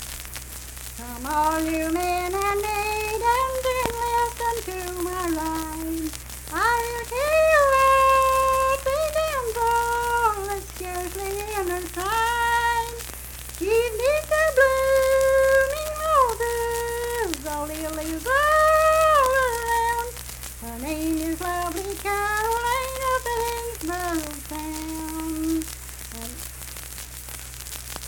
Unaccompanied vocal music performance
Verse-refrain 1(8).
Voice (sung)